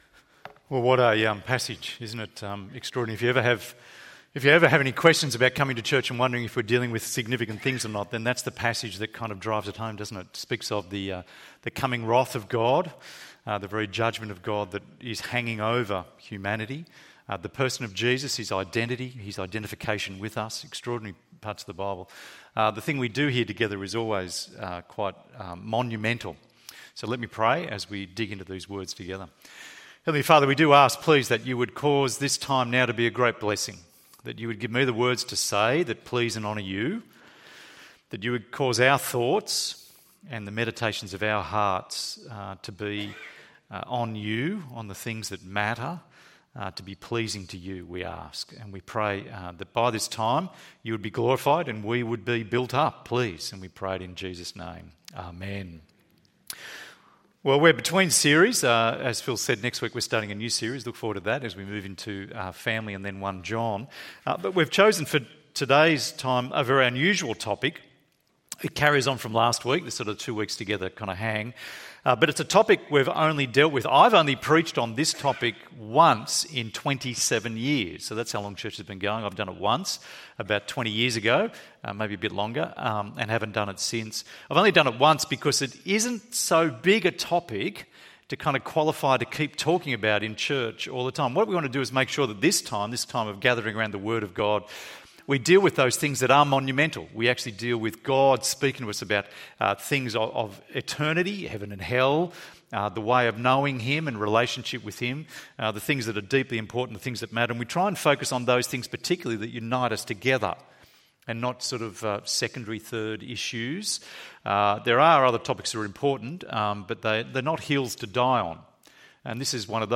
Baptism ~ EV Church Sermons Podcast